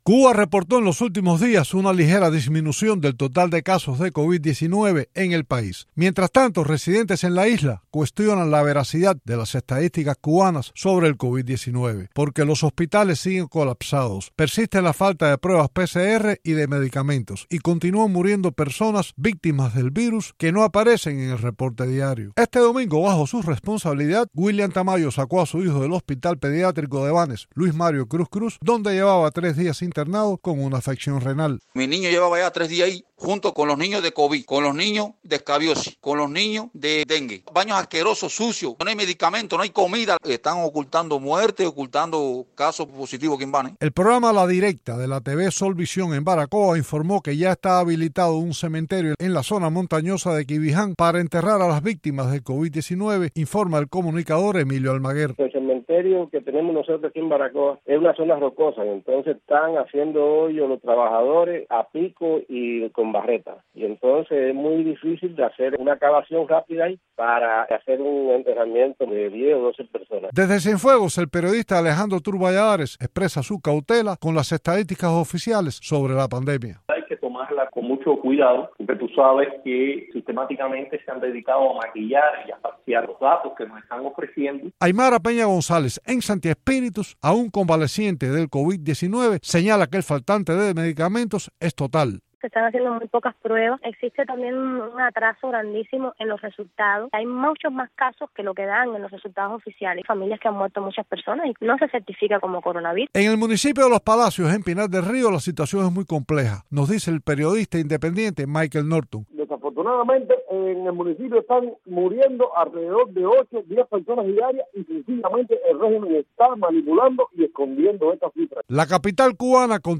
Cubanos entrevistados por Radio Televisión Martí cuestionaron este martes la veracidad de las estadísticas oficiales sobre el COVID-19 que, en los últimos días, muestran una disminución de los contagios y casos activos en el país.